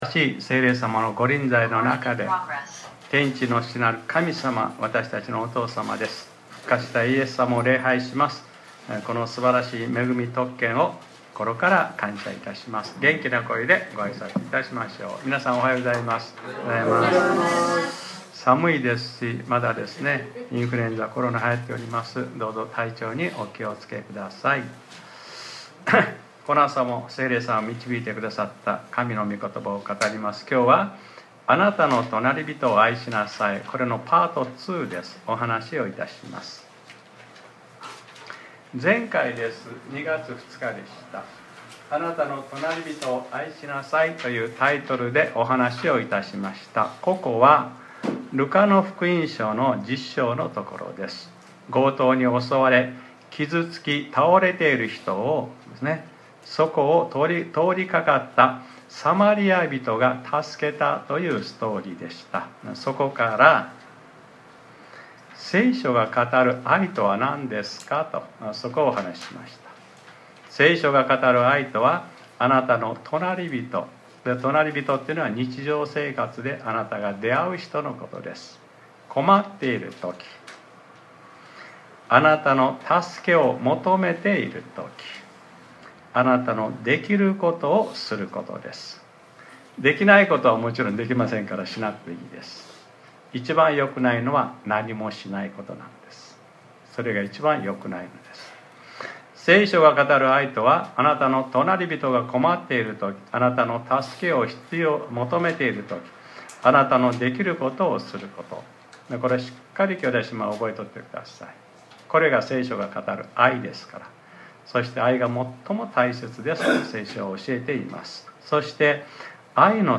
2025年02月23日（日）礼拝説教『 あなたの隣人を愛しなさい-２ 』